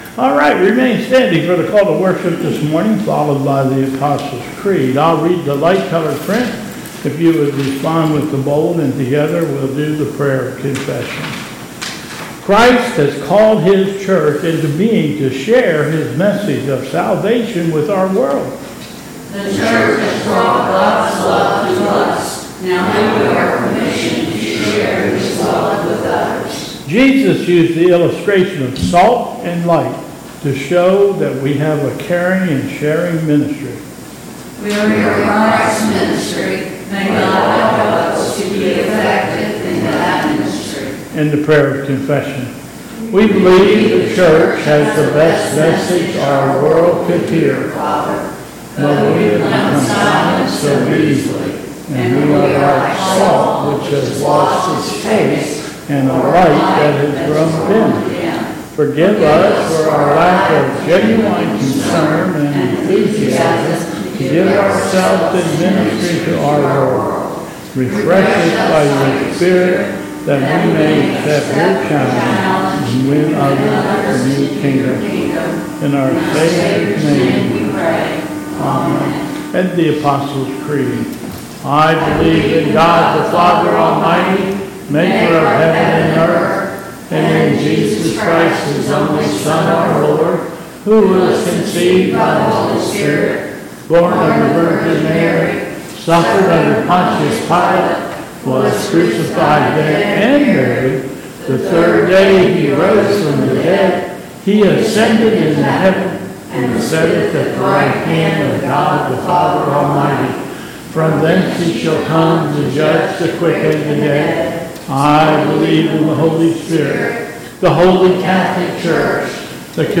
Bethel Church Service
Call to Worship...
...Apostle's Creed and Gloria Patria